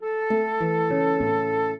flute-harp
minuet7-11.wav